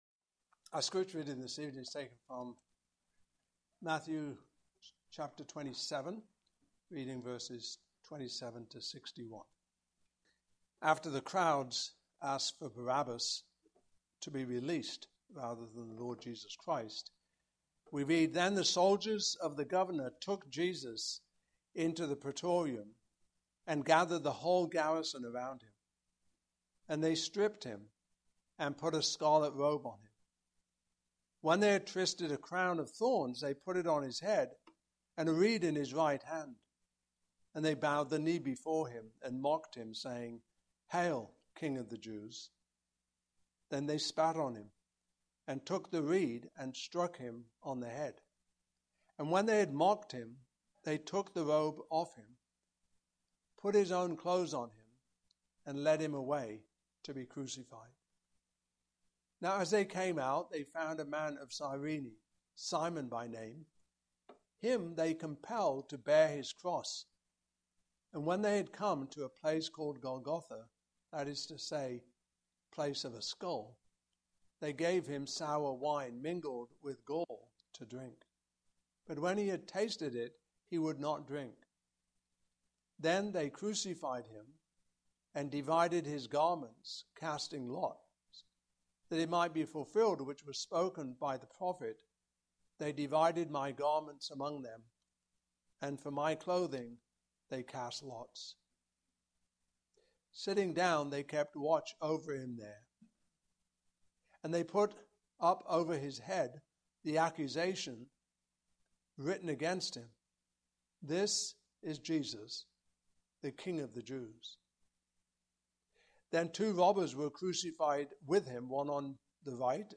Service Type: Evening Service Topics: Good Friday